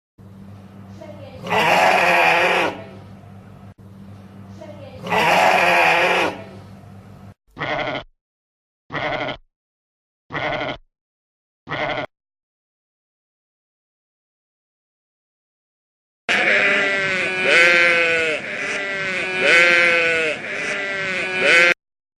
صوت خروف
الجواب: صوت الخروف يسمى: بالمأمأة
صوت-خروف-صوت-الخروف-اصوات-خرفان.mp3